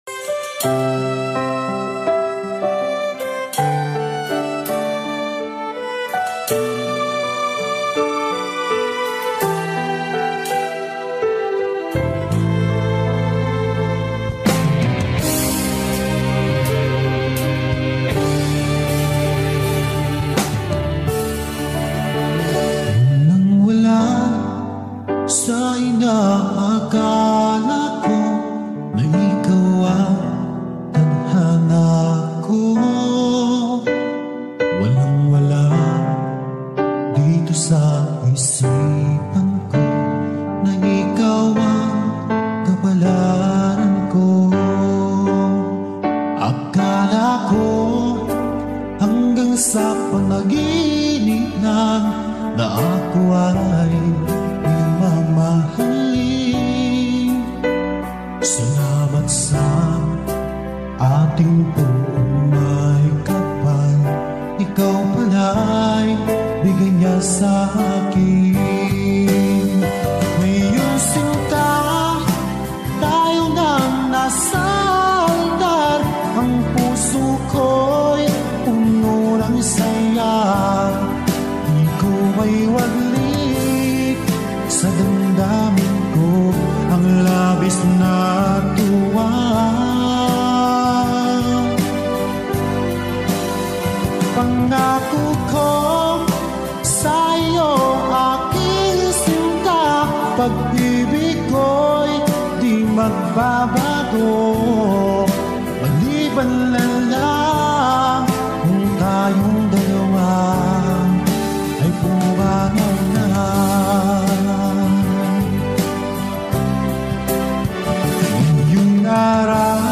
heartfelt OPM ballad